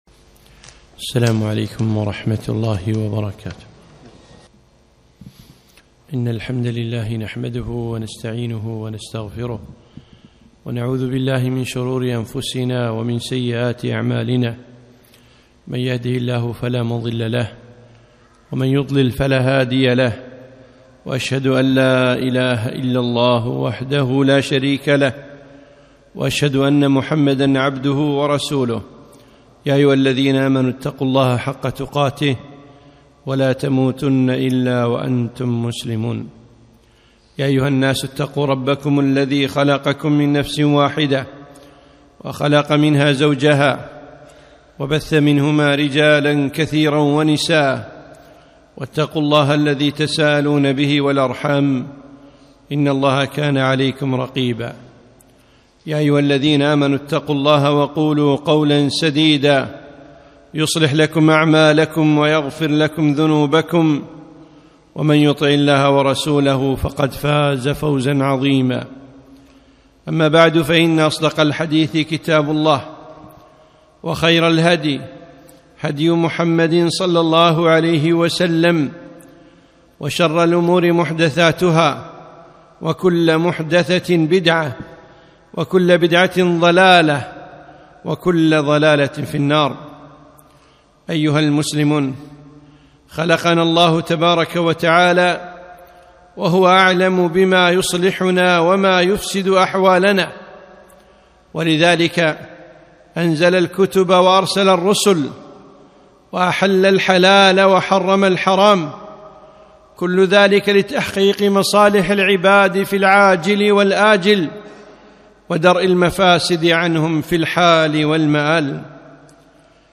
خطبة - أدوا الأمانة